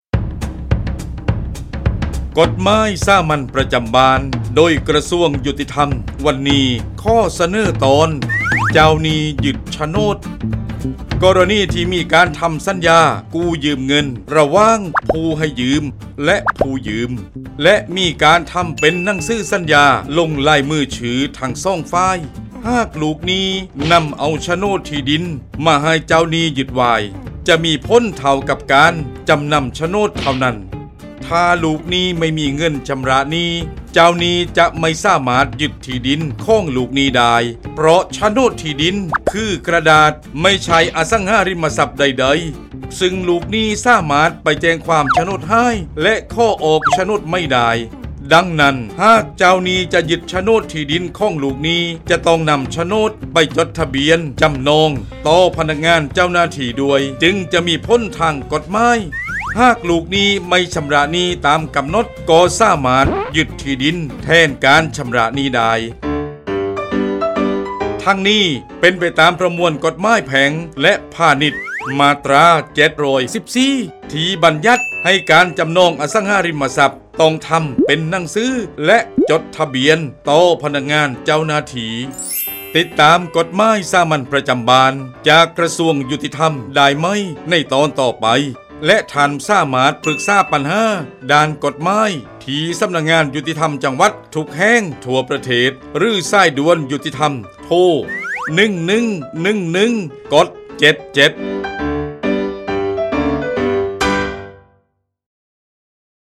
กฎหมายสามัญประจำบ้าน ฉบับภาษาท้องถิ่น ภาคใต้ ตอนเจ้าหนี้ยึดโฉนด
ลักษณะของสื่อ :   คลิปเสียง, บรรยาย